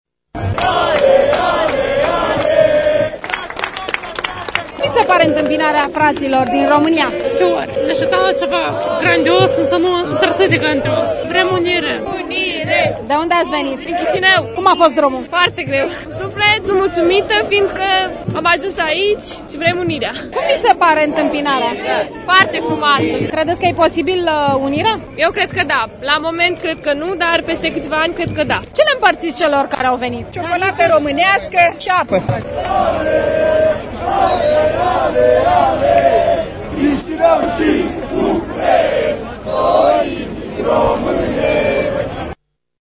Tinerii unionişti care au plecat acum o săptămână pe jos din Chişinău s-au aflat azi după-amiază în Piaţa Universităţii, din Capitală.